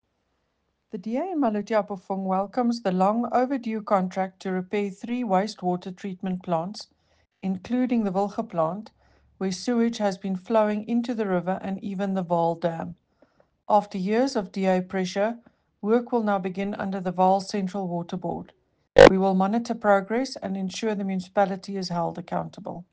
Afrikaans soundbites by Cllr Eleanor Quinta and Sesotho soundbite by Cllr Ana Motaung.